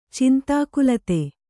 ♪ cintākulate